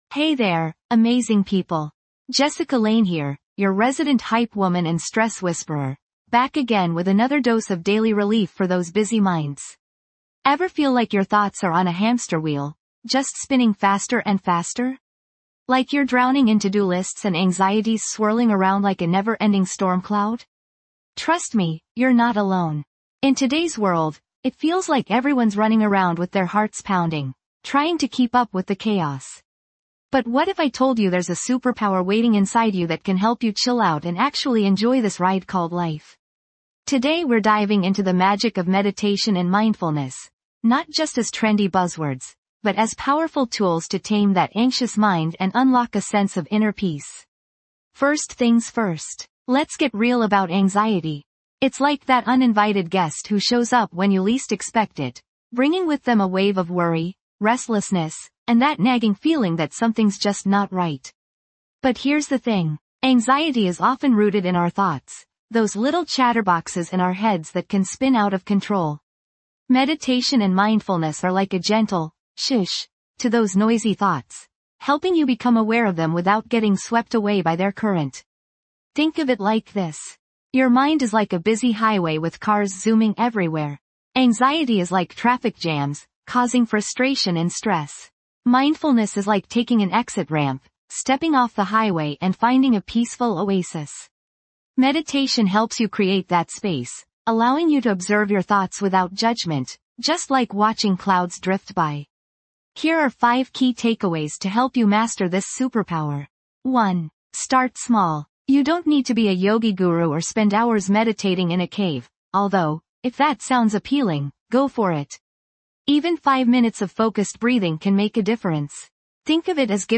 Through guided meditations, mindfulness exercises, and soothing soundscapes, we help you release tension, quiet your racing thoughts, and cultivate a sense of deep relaxation.